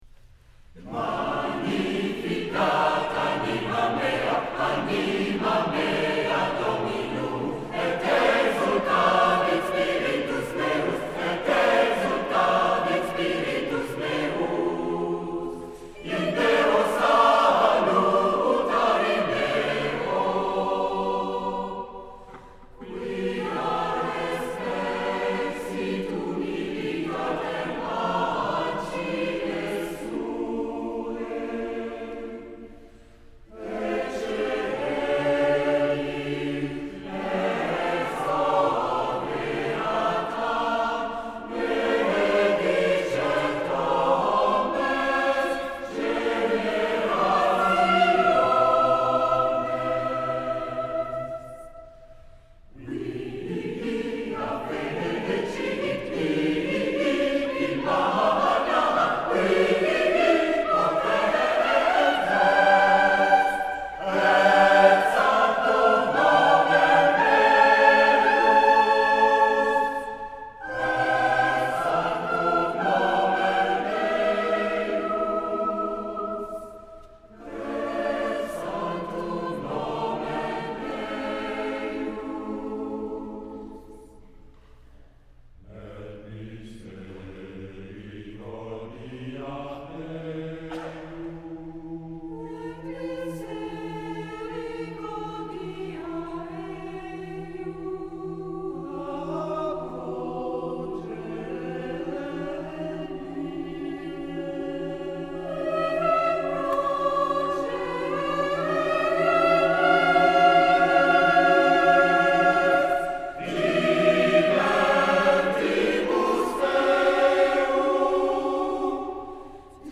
Magnificat
oeuvre pour choeur mixte SATB a cappella